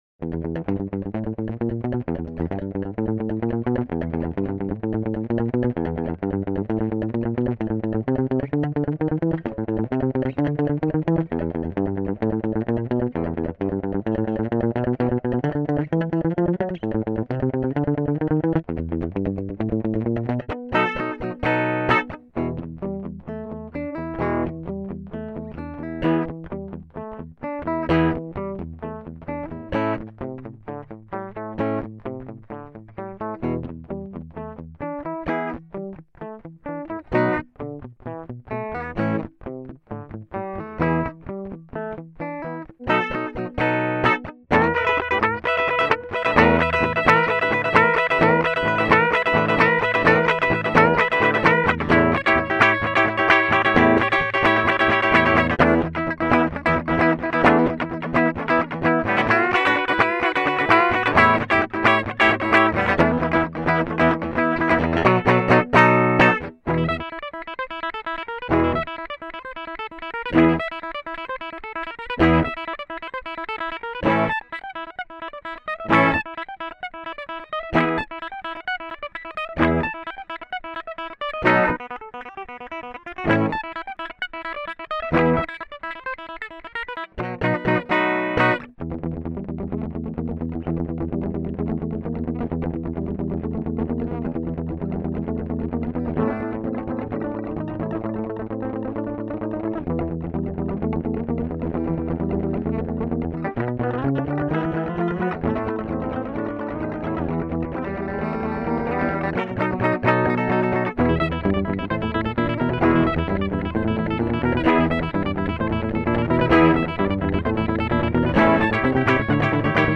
The piece encompasses the whole of a Telecaster's range he tells me. Recorded via HZ into a UMC204HD.